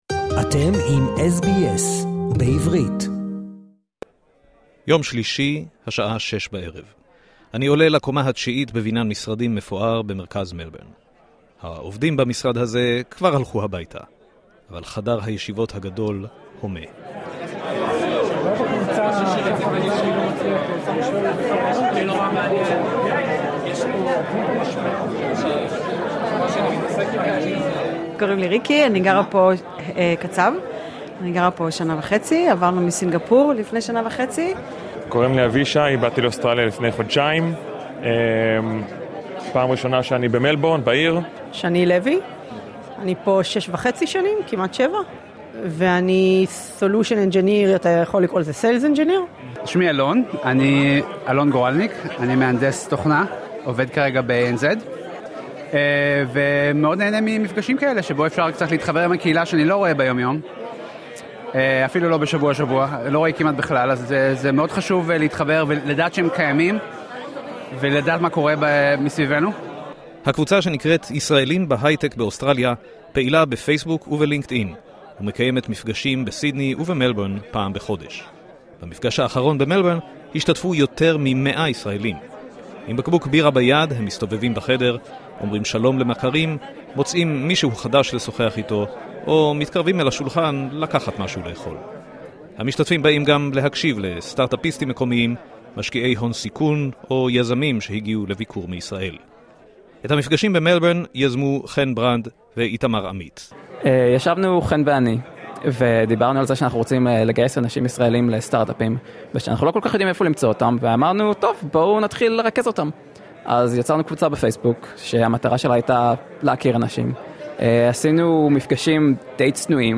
ישראלים וישראליות לשעבר שעובדים בהייטק באוסטרליה מתכנסים פעם בחודש כדי ליצור קשרים מקצועיים וחברתיים. במפגש סואן במלבורן שמענו סיפורים על רילוקיישן, חיפושי עבודה, תרבות העבודה המקומית, וועל הצורך בקהילתיות דווקא בתקופה הזו.